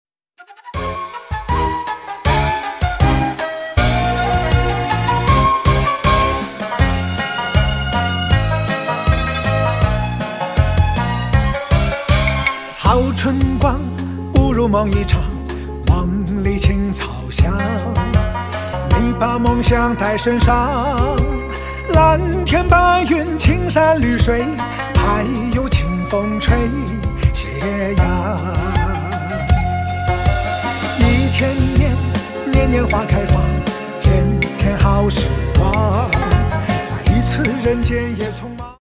High Speed Crusher | Powerful Crusher sound effects free download
High-Speed Crusher | Powerful Crusher Machine for Big Plastic Chemical PP PE Bucket/Drum/Cask. Discover our high-speed plastic crusher, designed for powerful and efficient shredding!